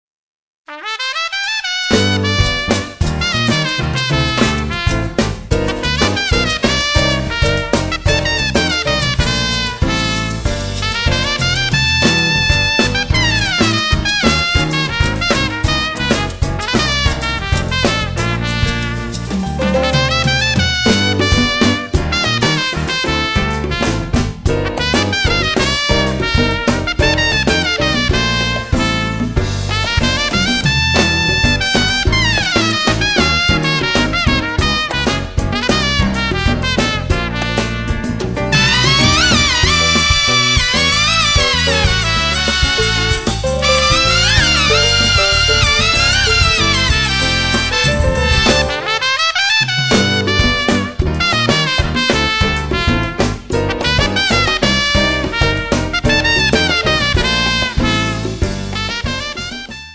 tromba, flicorno